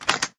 PickItem1.ogg